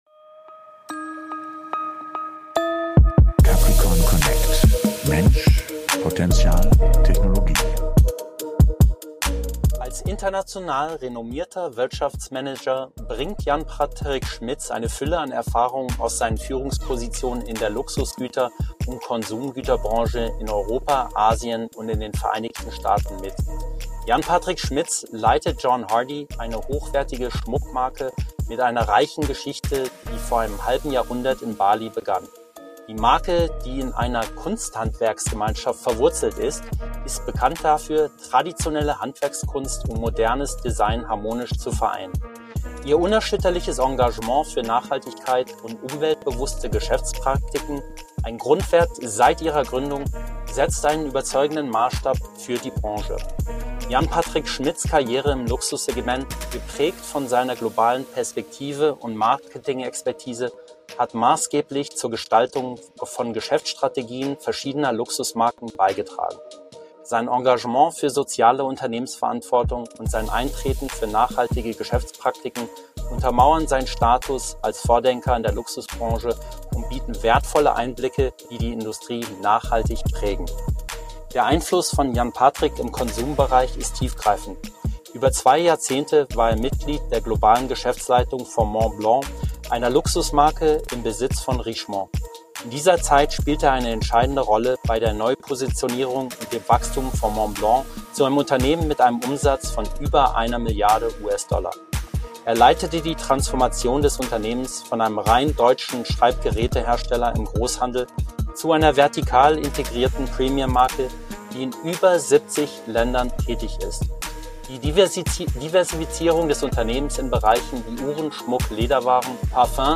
Erfahre wie Handwerkskunst und High-Tech im Luxussegment zusammenwirken, welche Rolle Künstliche Intelligenz in Design und Kundenbeziehung spielt, was moderne Talente mitbringen sollten, um in einer sich schnell wandelnden Arbeitswelt zu bestehen, und warum kulturelle Kompetenz in einer global vernetzten Wirtschaft zum entscheidenden Erfolgsfaktor wird. Ein Gespräch über Werte, Wandel und Vision – mit vielen konkreten Impulsen für Entscheider:innen, Kreative und Führungspersönlichkeiten.